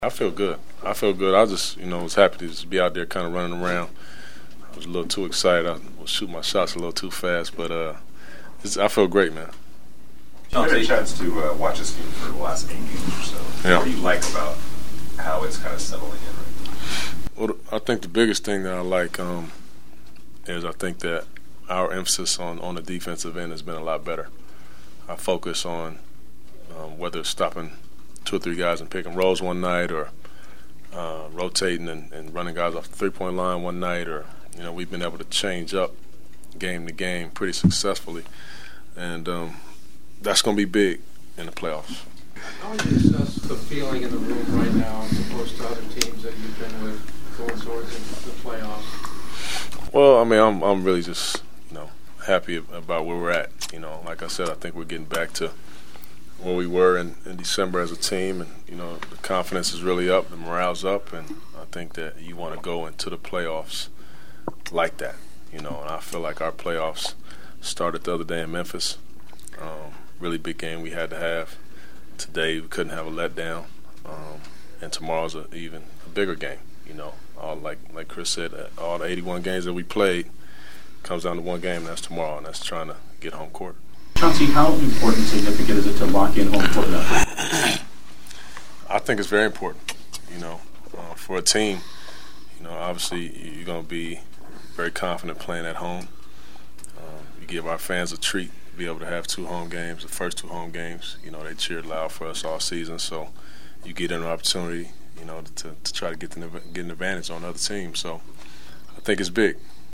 Chauncey Billups returned from his strained groin for L.A. to get in 17 minutes of work and was rusty as expected missing all 5 of his field goals (4 of them from 3 point land) and he picked up 5 assists but most importantly felt fine as he told us afterwards…